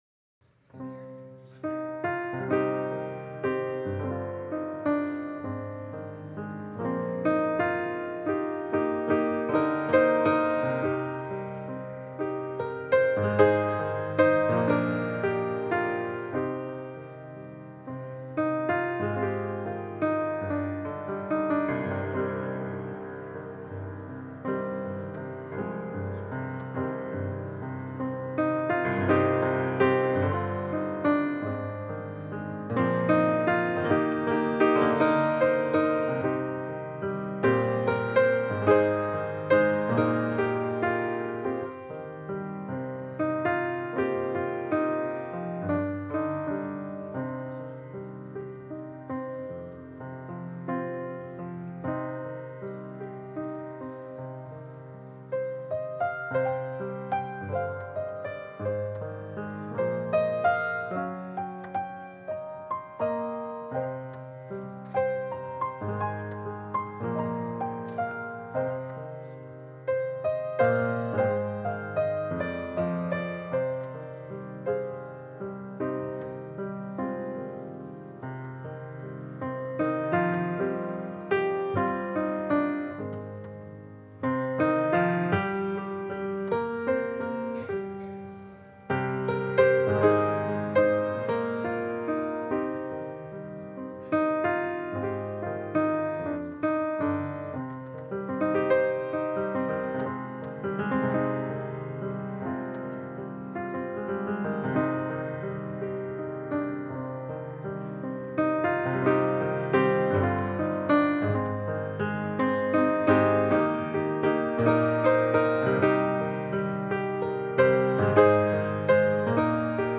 December 14, 2025 O Come, O Come, Emmanuel – Christmas Pageant Preacher: St. John's Youth Service Type: Sunday Service This year’s pageant comes from High River United Church in Alberta.